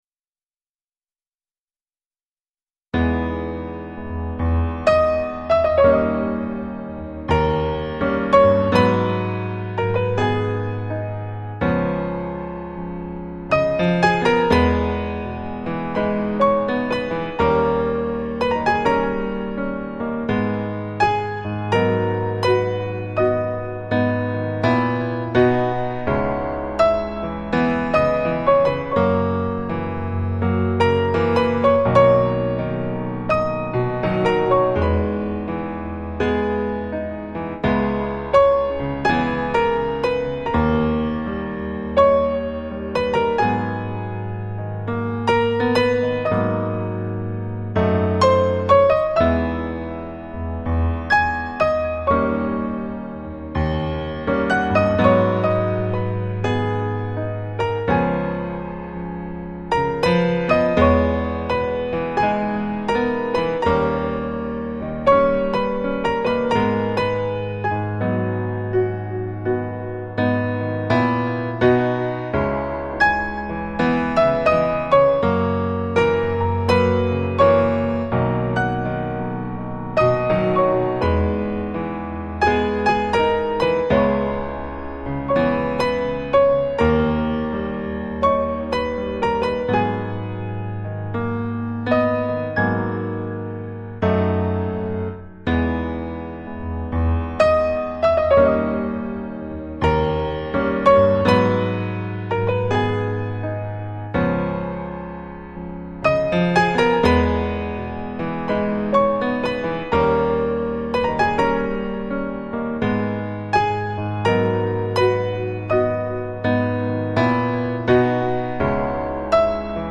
FLAC Жанр: Relax, Chillout, Jazz Издание